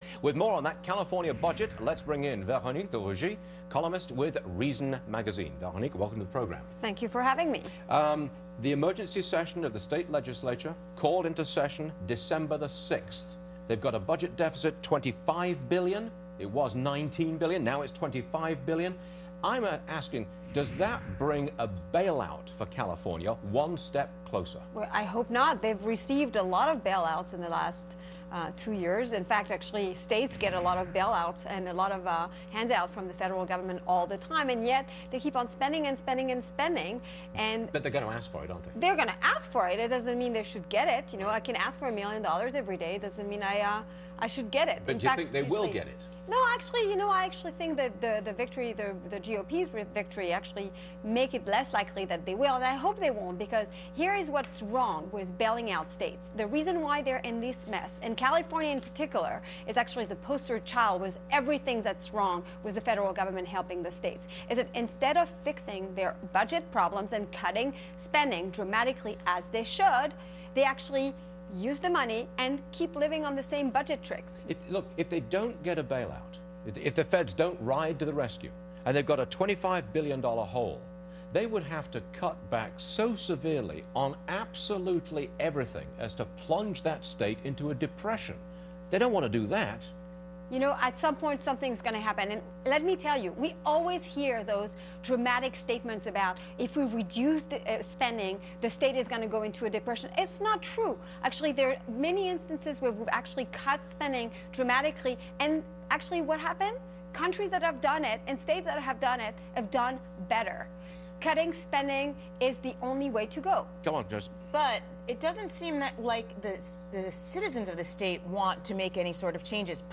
Reason monthly columnist Veronique de Rugy, an economist at the Mercatus Center, appeared on Varney & Co. to discuss California's growing deficits and whether this will lead to a federal bailout of the Golden State.